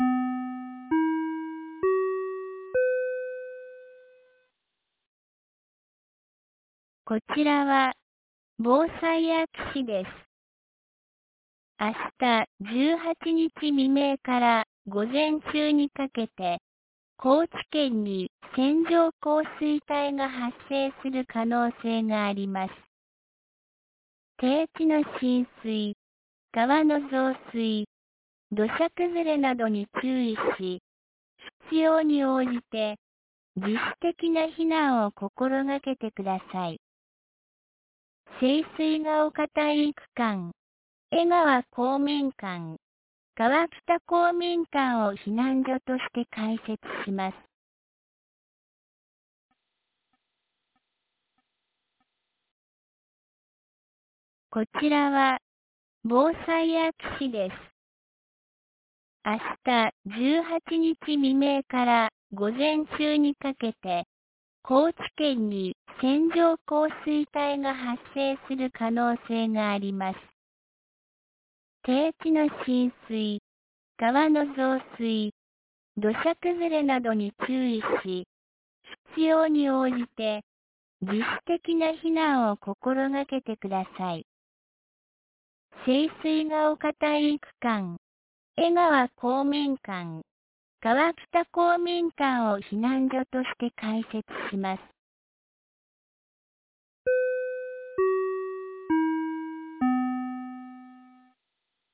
2024年06月17日 16時59分に、安芸市より川北、江川へ放送がありました。